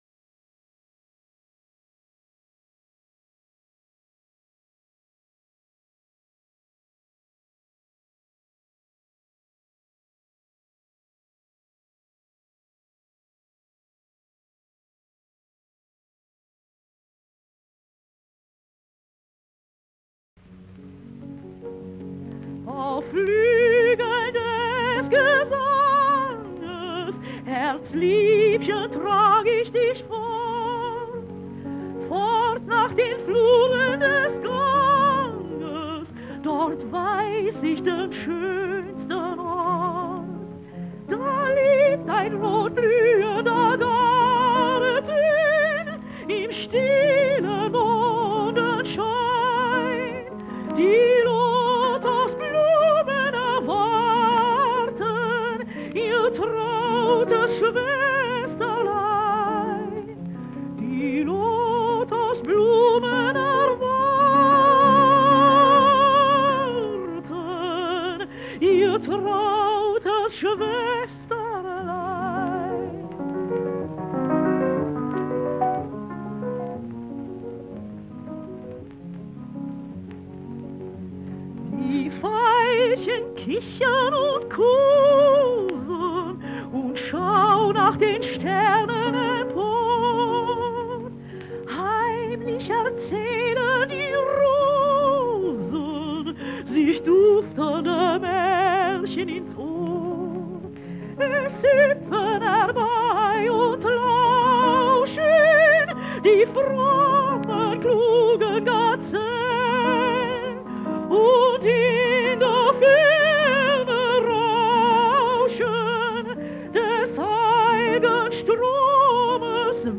La versió per a mi quasi referencial del tot, deguda a Victoria de los Ángeles la vaig desestimar per estar acompanyada en un preciós arranjament orquestral que semblava que donava prioritat a l’elecció, i per això em vaig estimar més que totes les versions fossin acompanyades a piano.